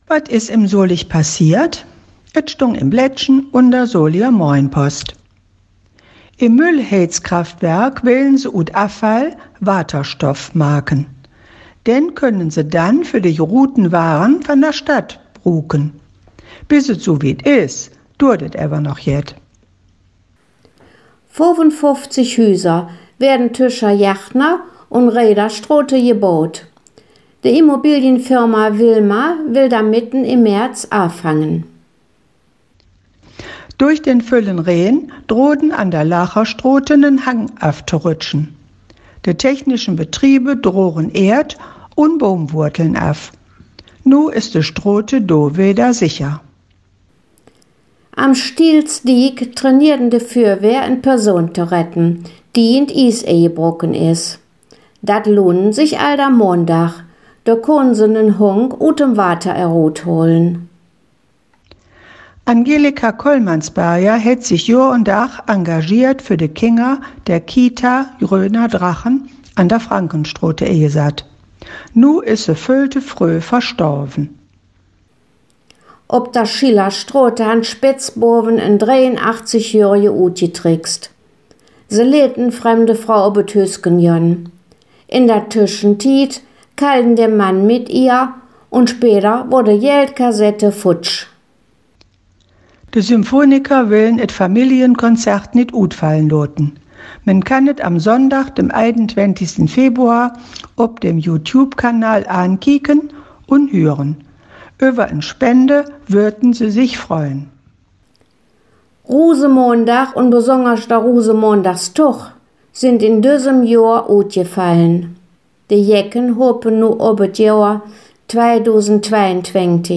Nöüegkeïten op Soliger Platt
Solinger-Platt-News-21kw07.mp3